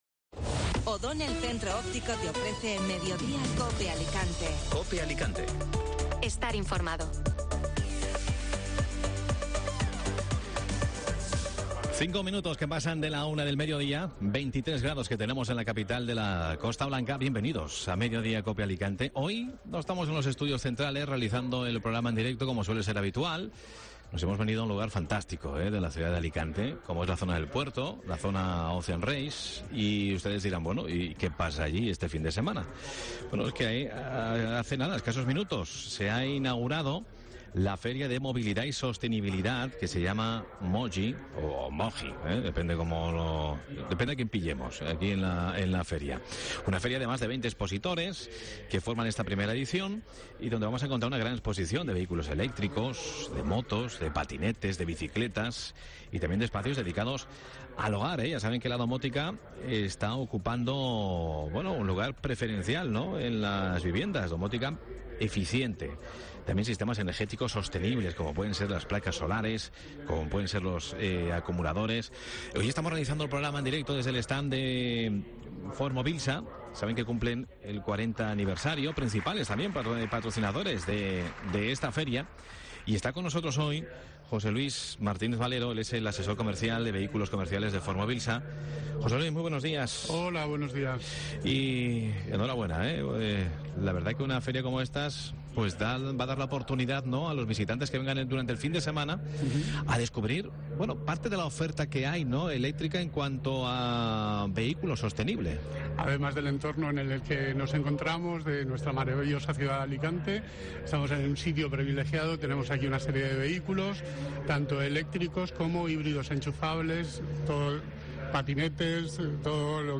Mediodía COPE Alicante emite el programa en directo desde la feria MOGY